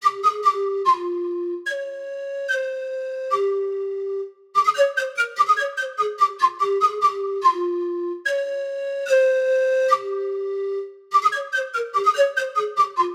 Feral_Flute.wav